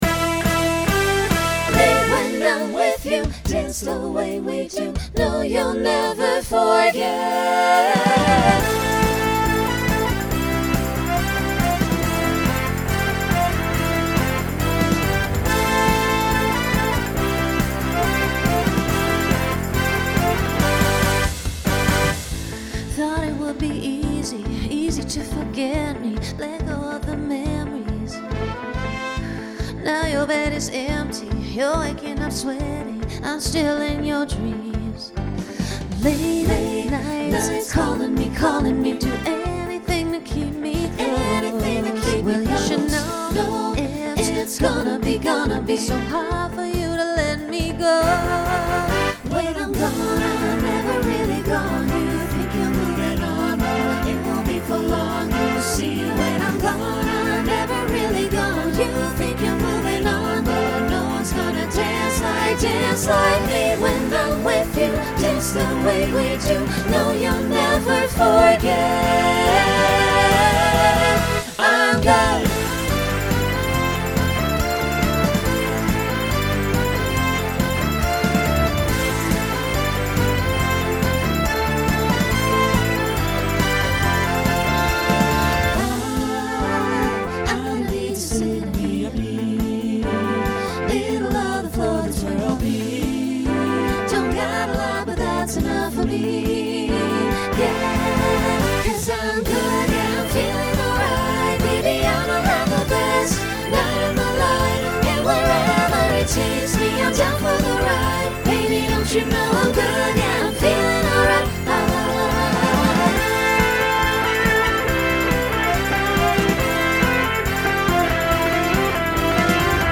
Genre Pop/Dance Instrumental combo
Voicing SATB